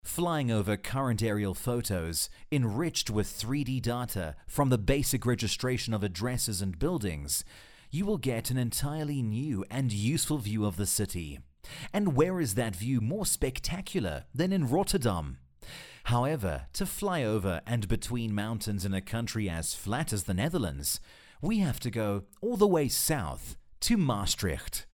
South African English Speaker with Neutral Accent.
Sprechprobe: Industrie (Muttersprache):